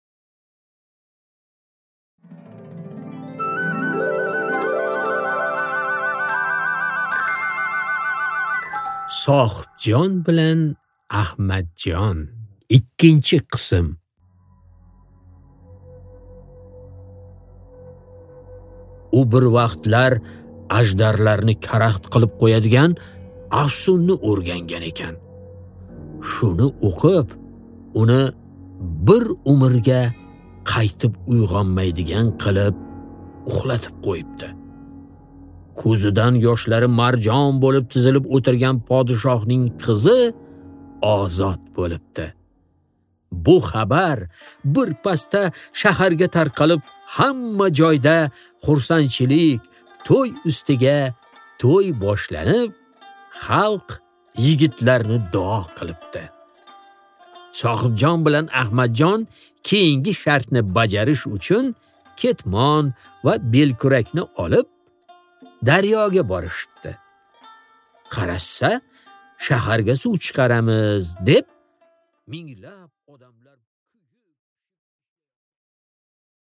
Аудиокнига Sohibjon bilan Ahmadjon 2-qism